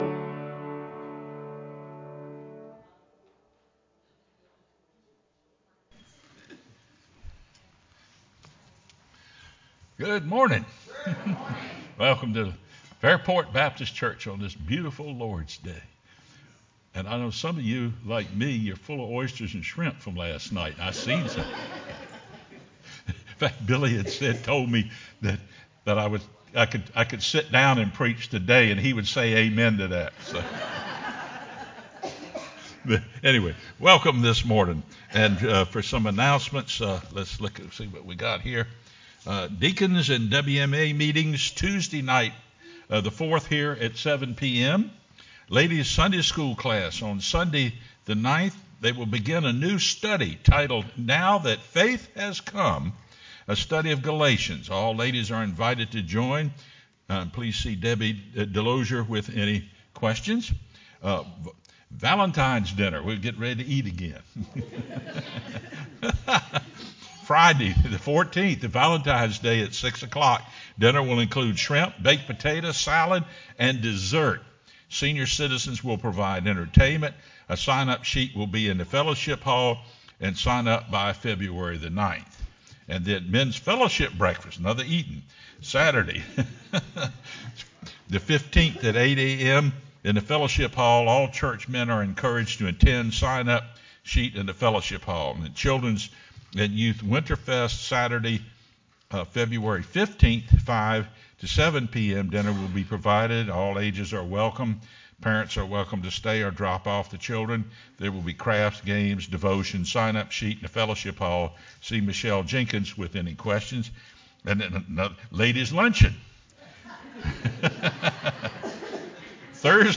sermonFeb02-CD.mp3